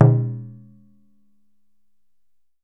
DJUN DJUN05R.wav